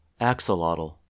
The axolotl (/ˈæksəlɒtəl/
En-us-axolotl.oga.mp3